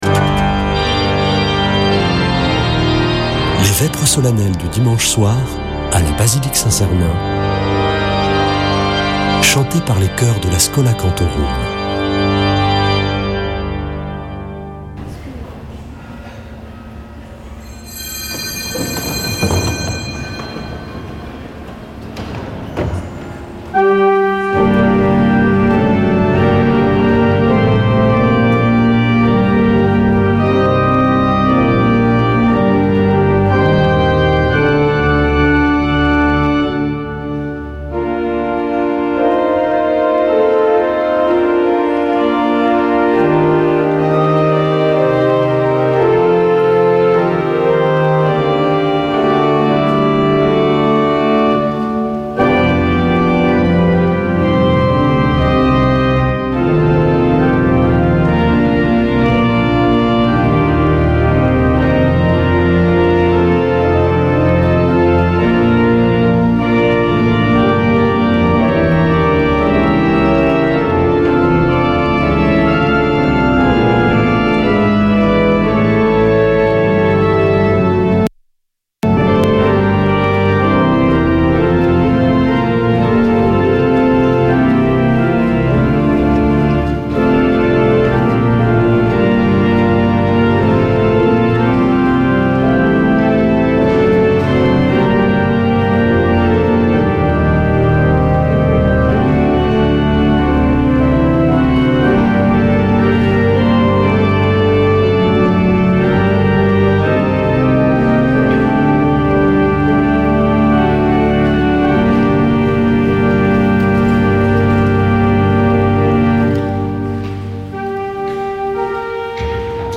Une émission présentée par Schola Saint Sernin Chanteurs